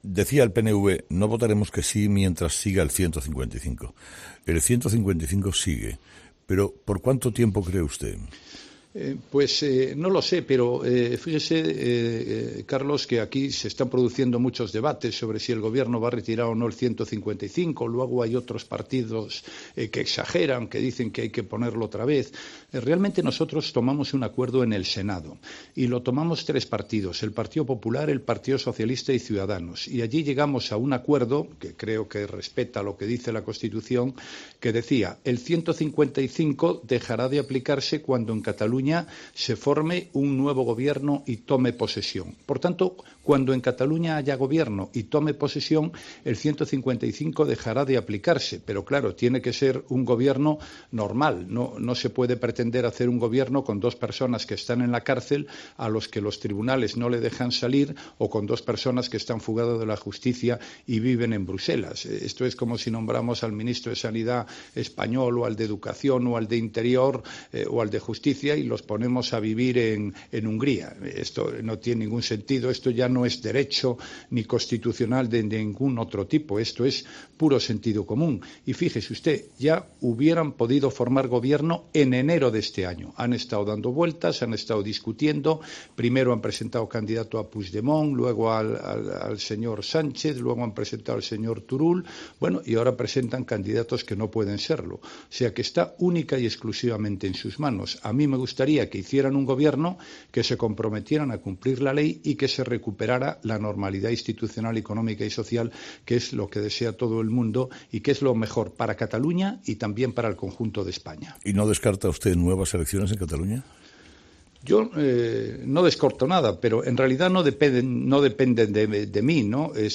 "Si en España es delito algo, lo normal es que se respete fuera. Pero Europa aún no está todavía completada", ha señalado el presidente durante su entrevista con Carlos Herrera en COPE